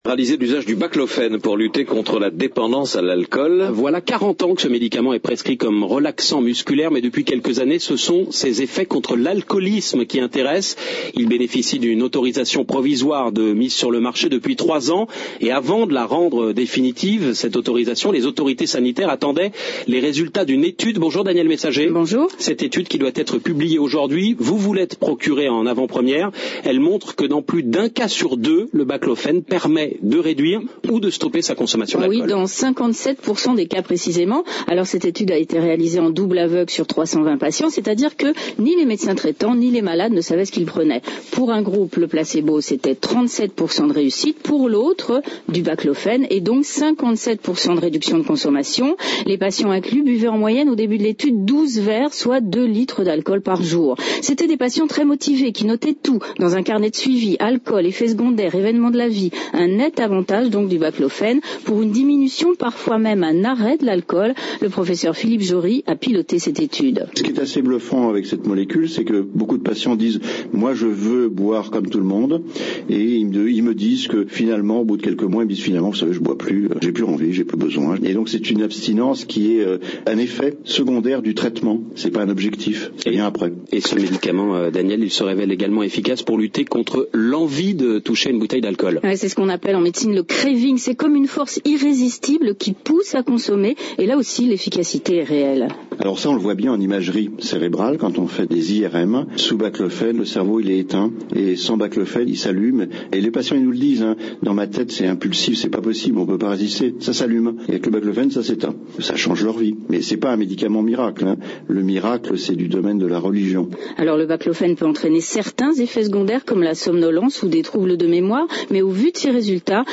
Les explications